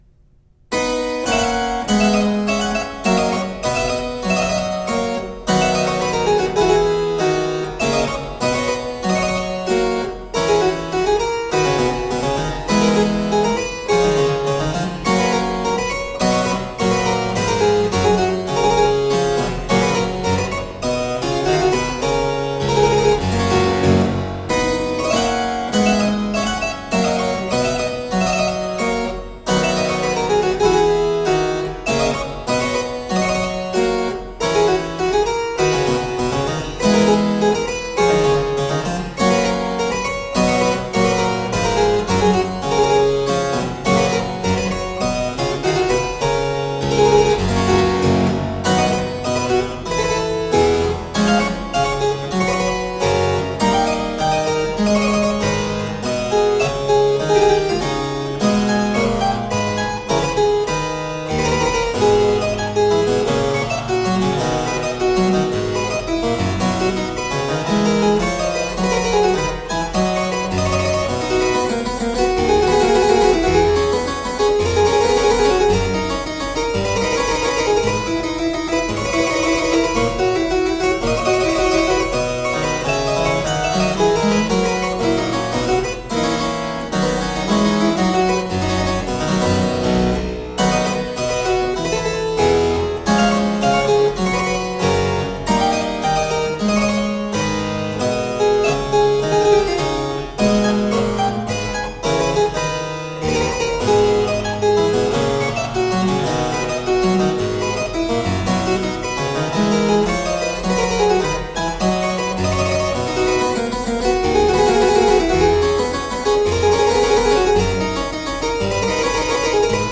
Classical, Baroque, Instrumental